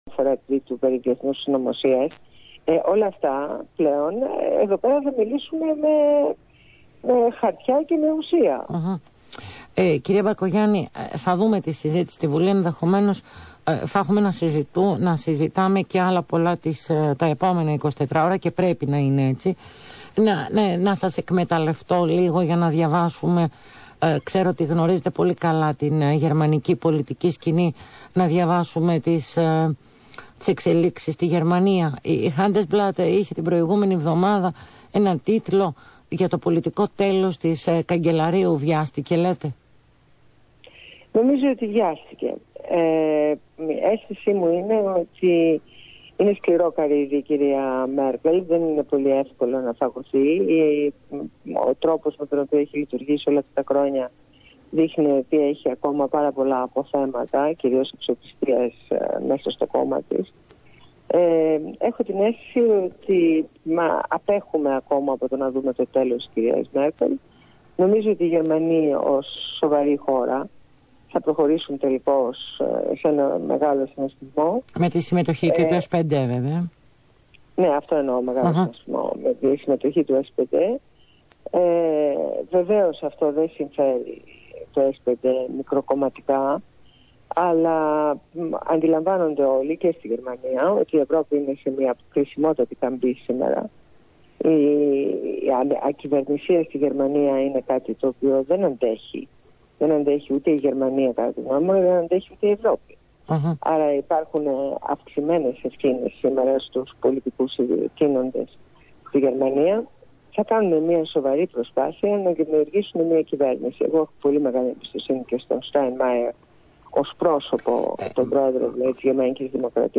Συνέντευξη στο ραδιόφωνο Αθήνα 9,84